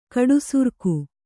♪ kaḍusurku